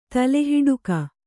♪ tale hiḍuka